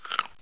eat-crunch2.wav